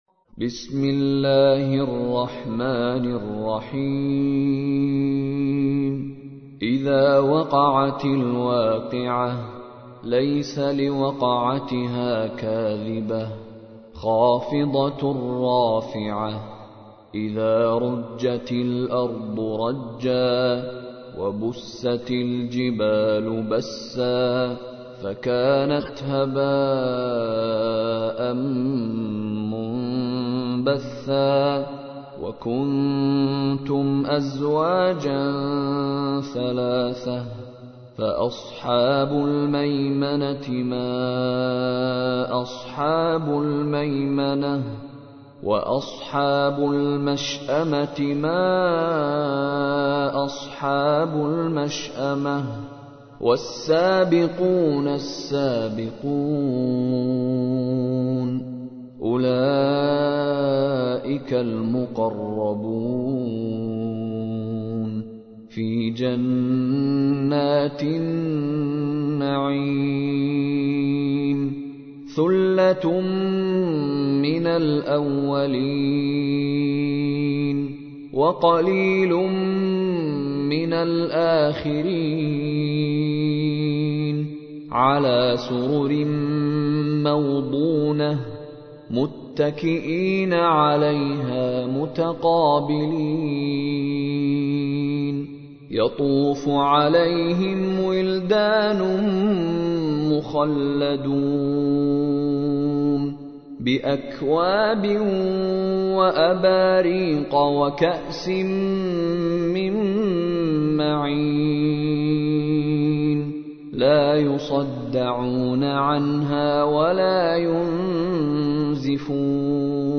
تحميل : 56. سورة الواقعة / القارئ مشاري راشد العفاسي / القرآن الكريم / موقع يا حسين